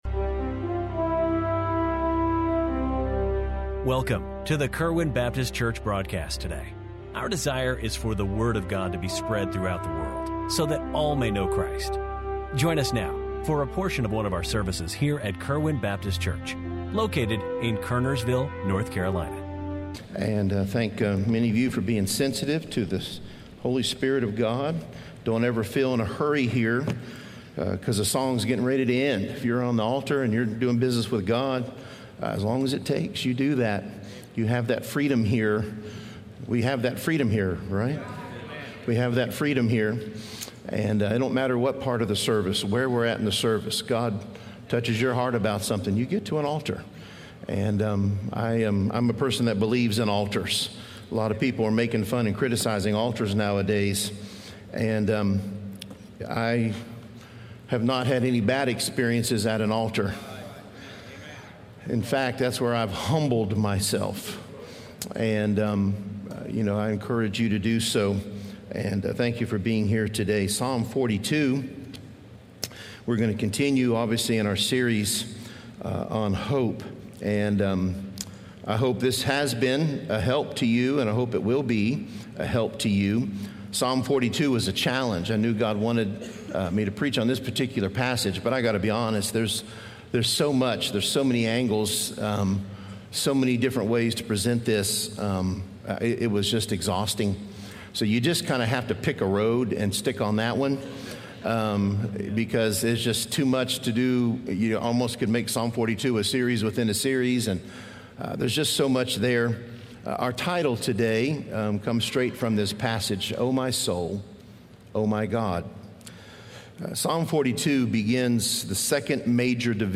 Kerwin Baptist Church Daily Sermon Broadcast